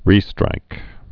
(rēstrīk)